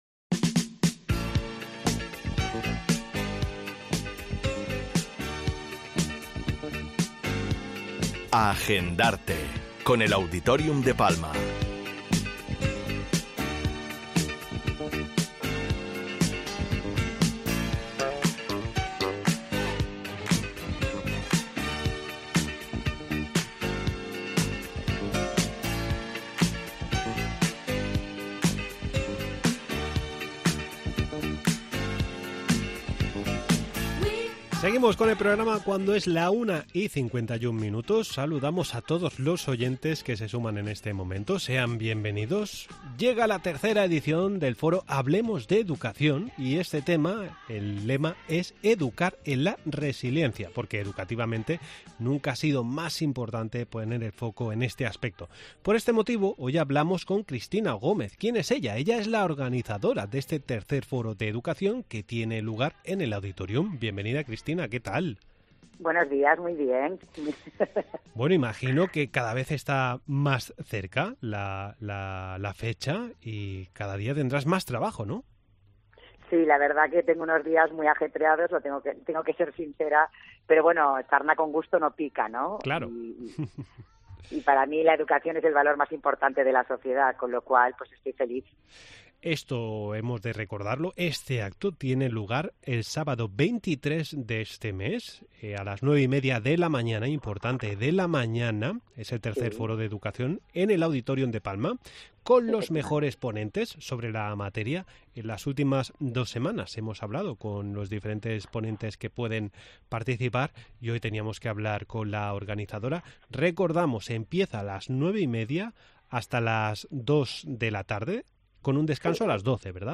Entrevista en 'La Mañana en COPE Más Mallorca', jueves 14 de septiembre de 2023.